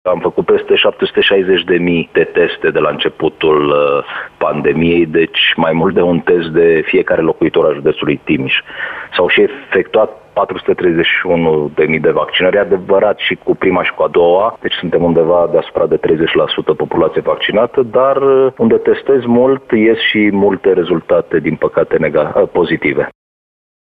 Precizarea a fost făcută de subprefectul de Timiș, Ovidiu Drăgănescu, într-o intervenție telefonică la Digi 24.